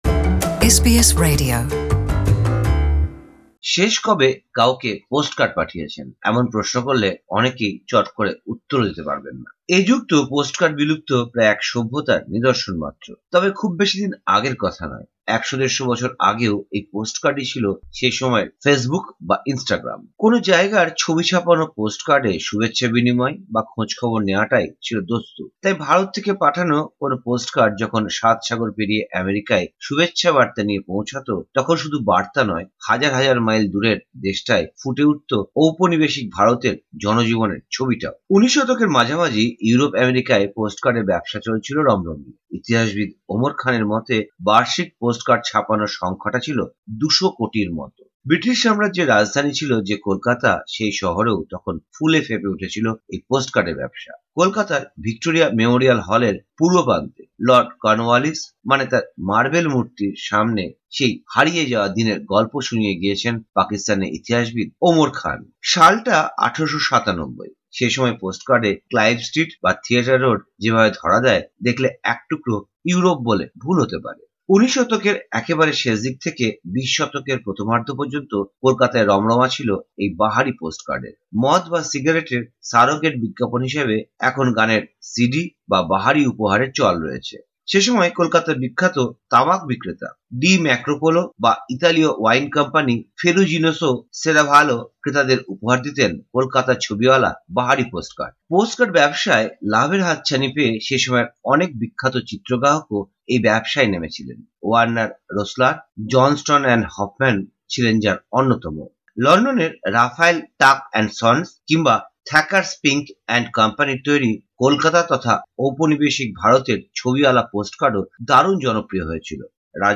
পোস্টকার্ডকে বলা হচ্ছে, এক শতাব্দী আগের ফেসবুক বা ইনস্টাগ্রাম। হারিয়ে যাওয়া সেই পিকচার পোস্টকার্ড নিয়ে প্রতিবেদনটি বাংলায় শুনতে উপরের অডিও প্লেয়ারটিতে ক্লিক করুন।